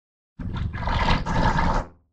PixelPerfectionCE/assets/minecraft/sounds/mob/guardian/land_idle3.ogg at mc116
land_idle3.ogg